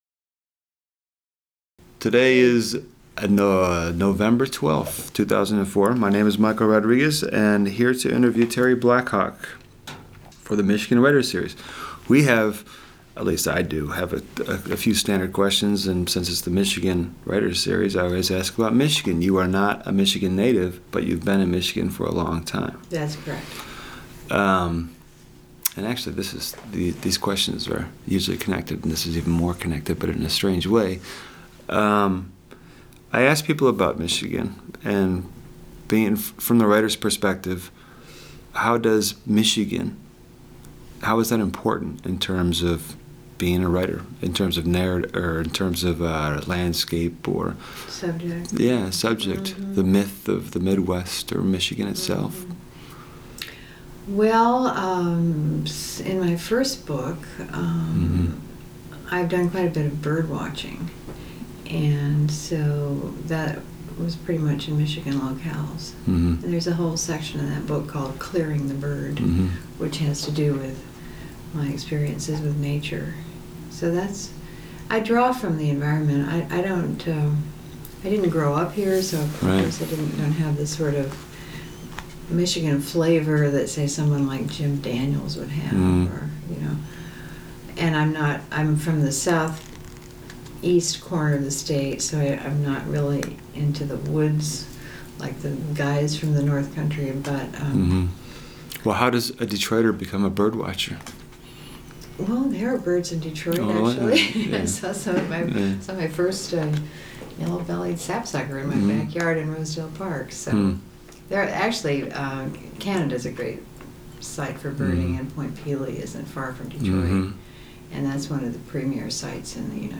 Recorded at the Michigan State University Libraries by the Vincent Voice Library on Nov. 12, 2004.